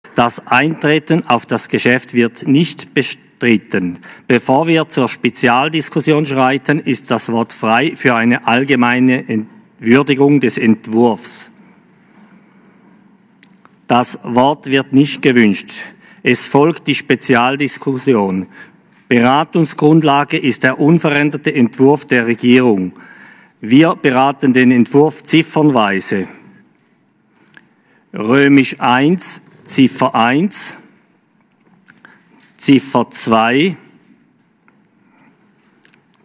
Session des Kantonsrates vom 14. bis 17. September 2020